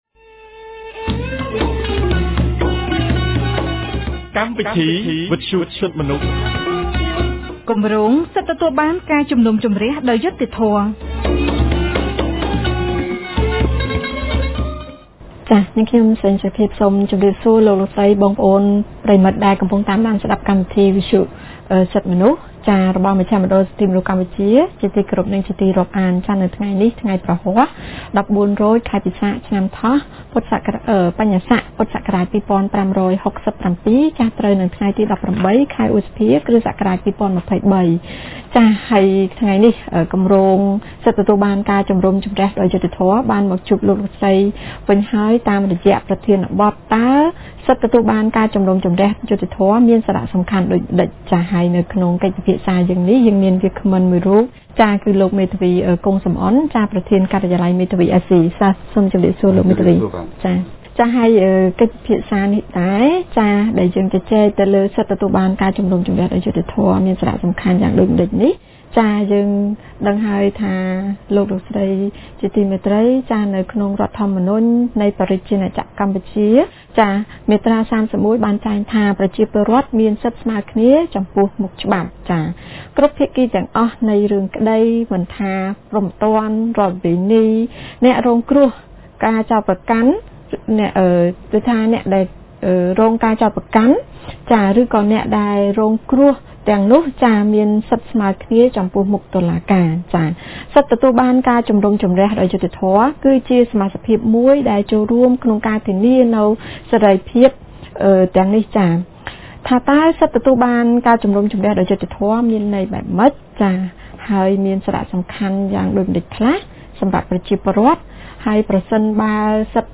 នៅថ្ងៃពុធ ទី១៨ ខែមិថុនា ឆ្នាំ២០២៣ គម្រាងសិទ្ធិទទួលបានការជំនុំជម្រះដោយយុត្តិធម៌នៃមជ្ឈមណ្ឌលសិទ្ធិមនុស្សកម្ពុជា បានរៀបចំកម្មវិធីវិទ្យុក្រោមប្រធានបទស្តីពី តើសិទ្ធិទទួលបានការជំនុំជម្រះដោយយុត្តិធម៌មានសារៈសំខាន់ដូចម្តេច?